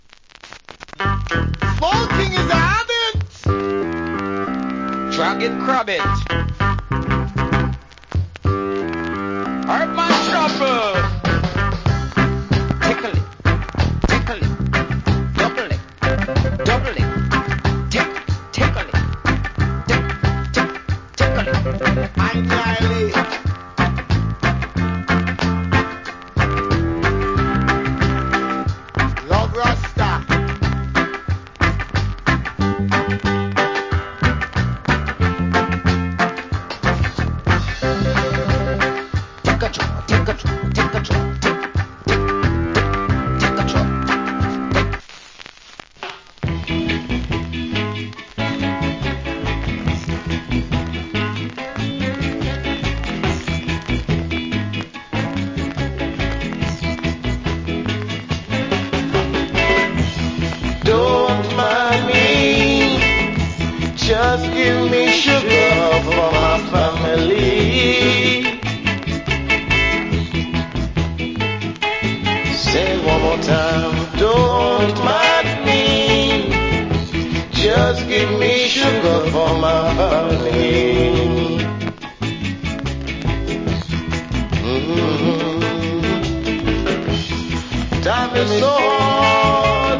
Killer MC + Organ Early Reggae Inst.